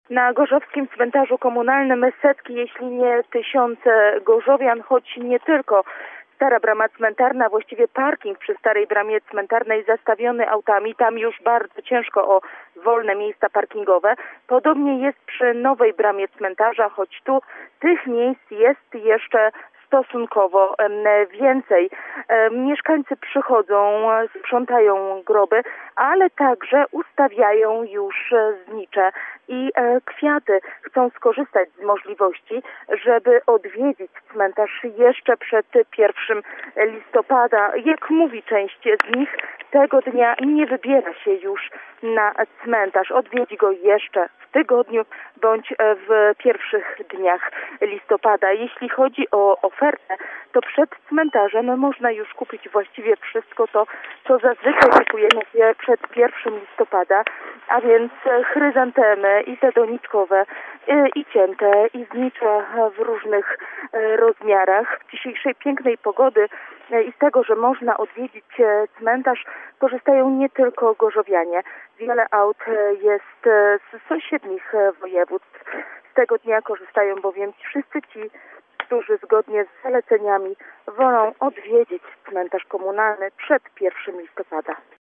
Mieszkańcy korzystają z ładnej pogody i porządkują groby swoich bliskich. Jak informuje nasza reporterka, na cmentarzu komunalnym przy ul. Żwirowej jest sporo osób.